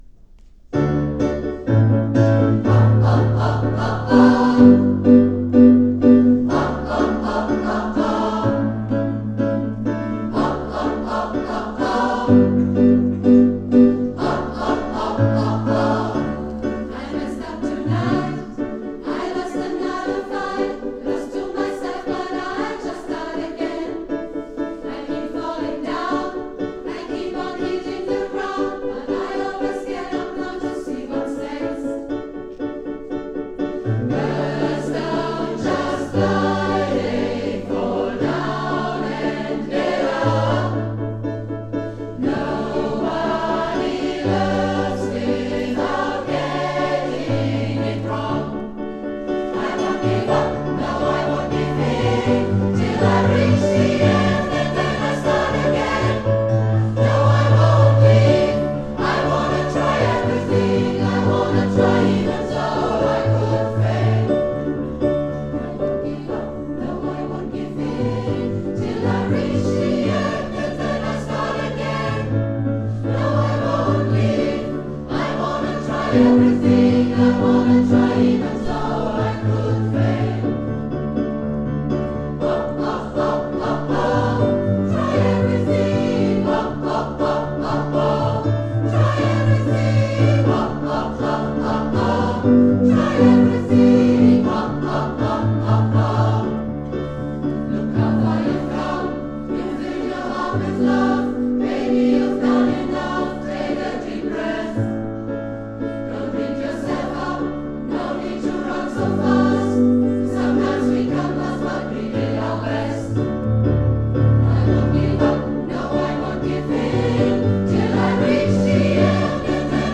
09 - Konzertaufnahmen - ChorArt zwanzigelf - Page 5
Kathy Kelly mit ChorArt zwanzigelf 17.03.2019 – Try Everything